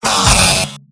Robot-filtered lines from MvM. This is an audio clip from the game Team Fortress 2 .
{{AudioTF2}} Category:Engineer Robot audio responses You cannot overwrite this file.
Engineer_mvm_painsharp08.mp3